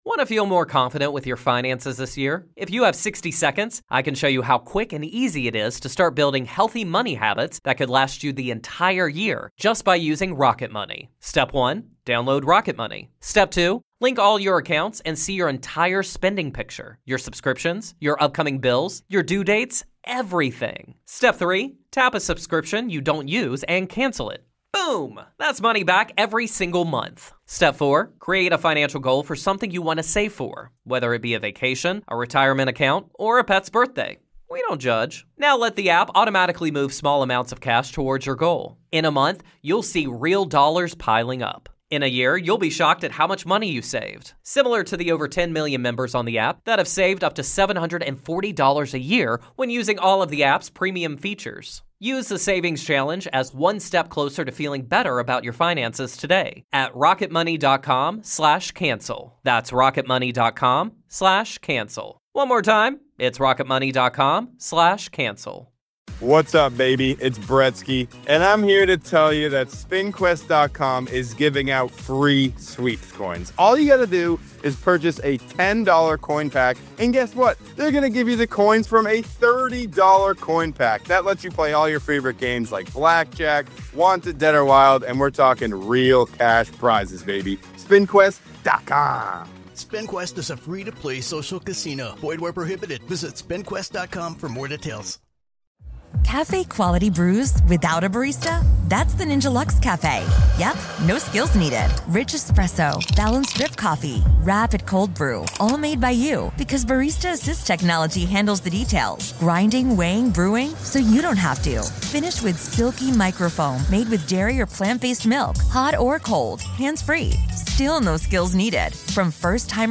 My HoneyDew this week is comedian Fortune Feimster!